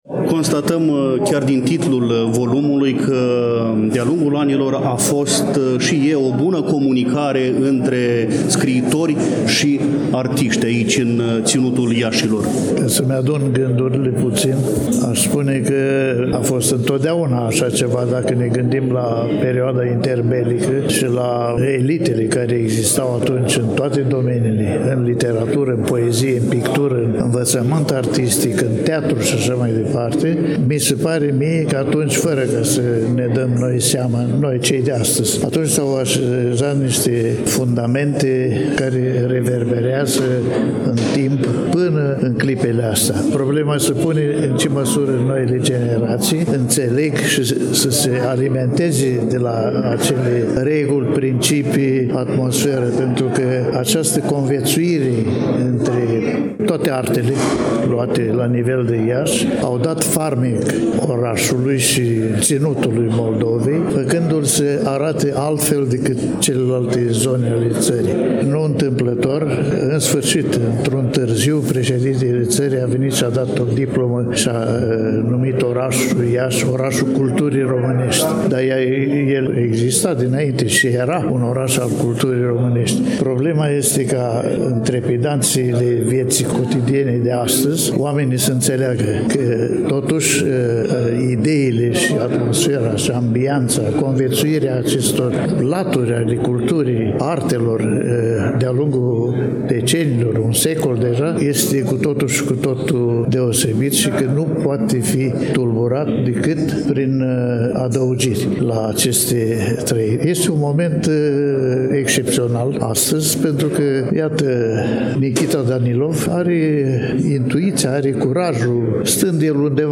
După cum bine știți, în emisiunea de astăzi relatăm de la prezentarea volumului Atelierele de pe Armeană, semnat de către scriitorul ieșean Nichita Danilov, carte lansată la Iași în ziua de  vineri, 5 aprilie 2024, începând cu ora 17 și 30 de minute, în incinta la Galeriei de Artă „Th. Pallady” de pe strada Alexandru Lăpușneanu, Numerele 7-9.